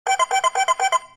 tone.mp3